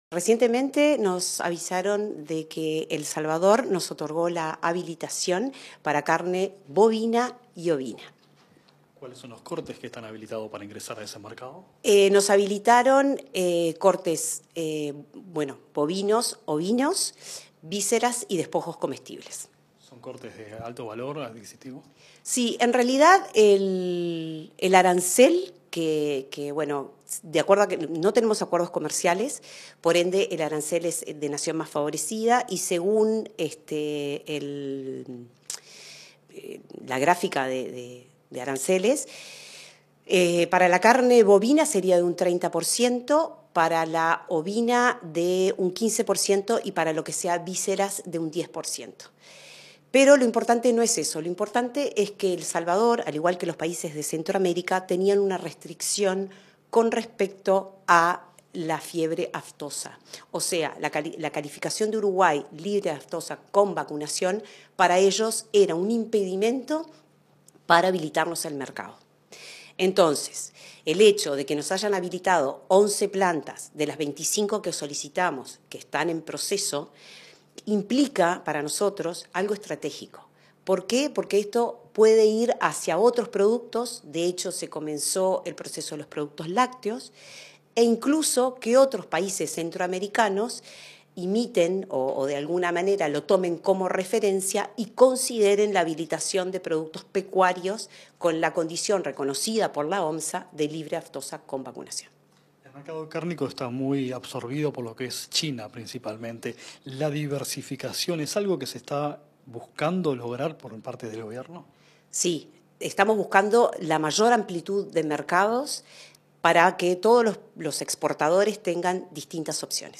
Entrevista a la directora de Asuntos Internacionales del MGAP, Adriana Lupinacci